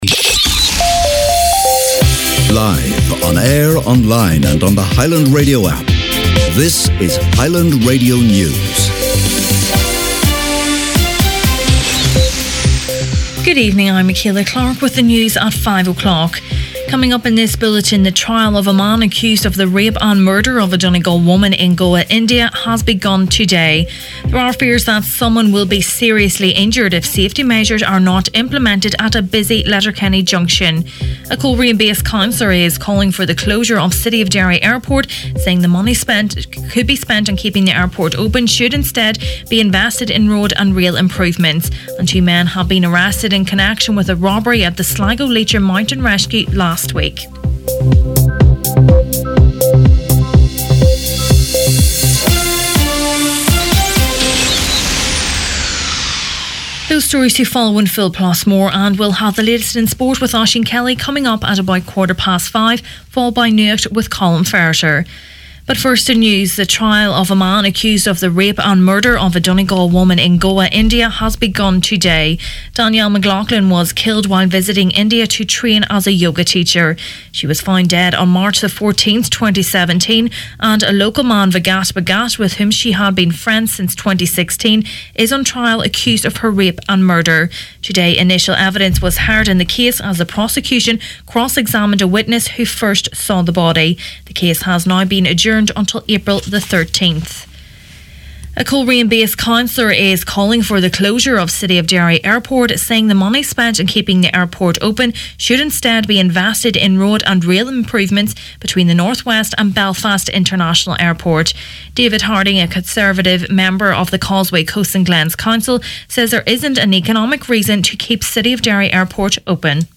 Main Evening News, Sport, Nuacht and Obituaries Friday 6th April